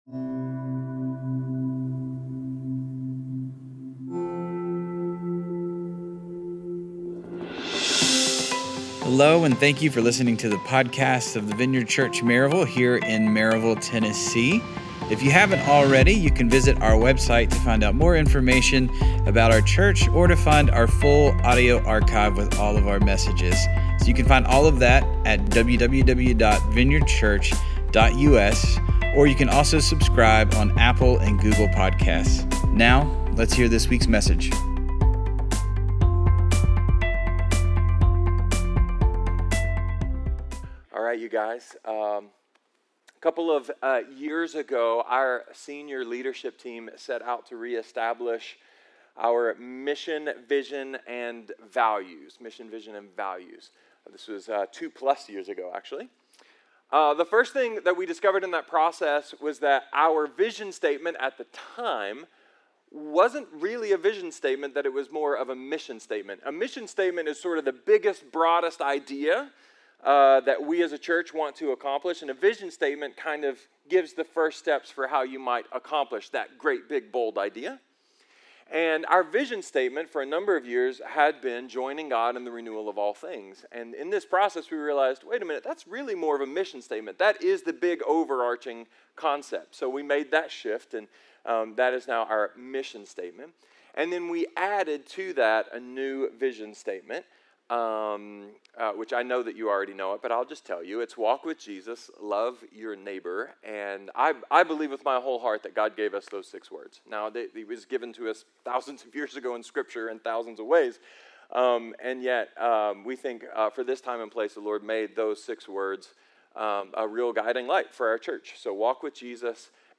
A sermon about what makes us unique, what makes us so hopeful, and what makes us so powerful.